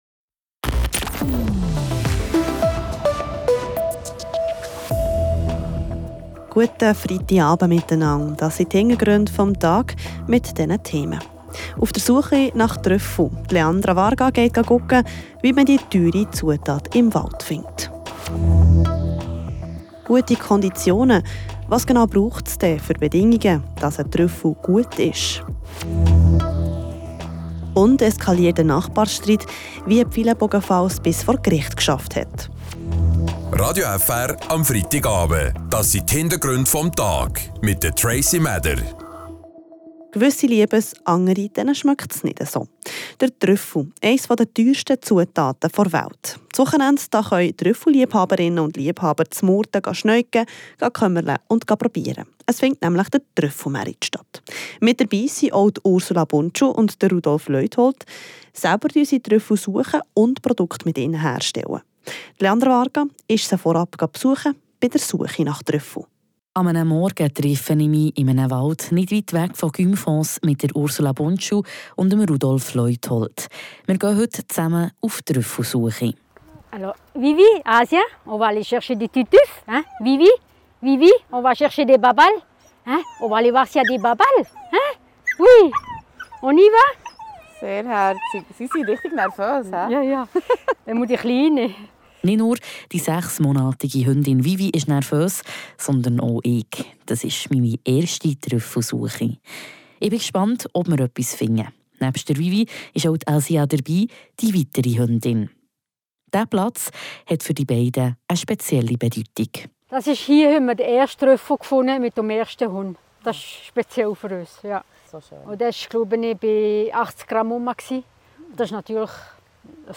mit zwei Trüffelproduzentinnen und -sucher im Wald unterwegs, um die perfekten Trüffel zu finden. Ausserdem hat es ein Streit zwischen Nachbarn bis vor das Kantonsgericht geschafft.